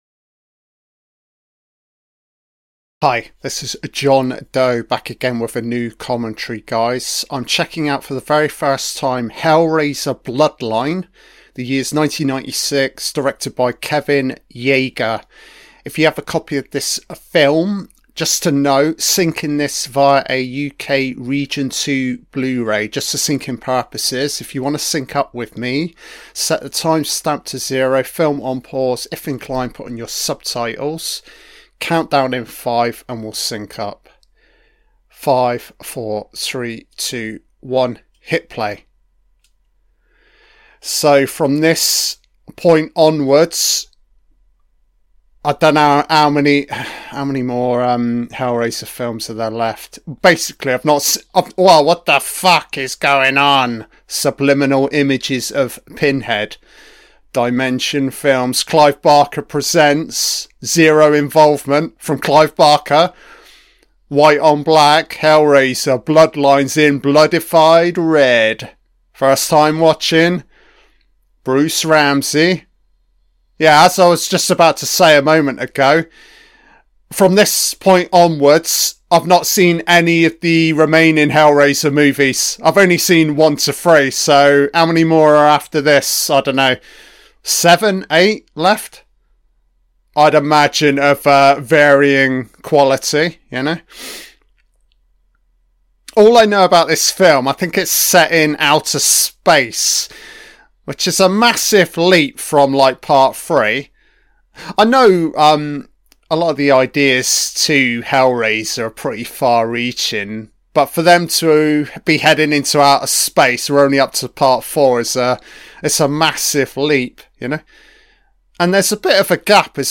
An Audio-Only Commentary on the 1996 film BLOODLINE